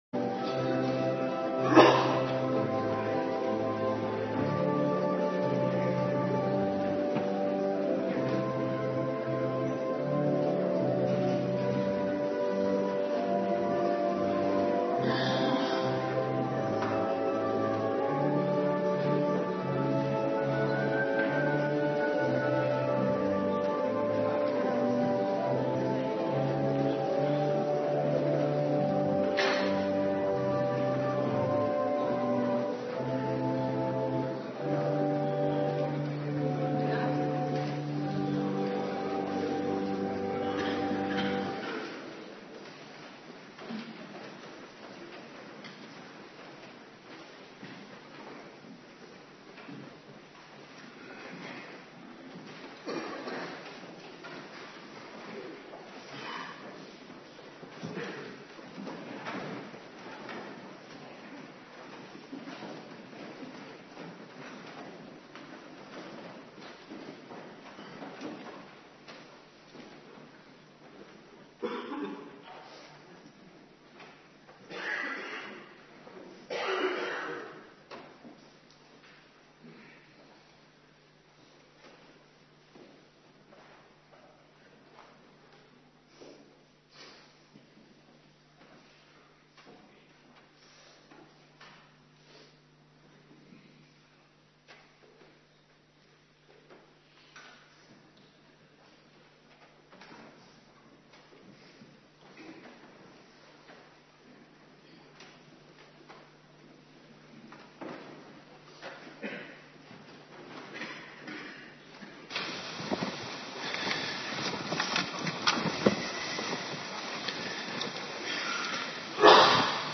Morgendienst Heilig Avondmaal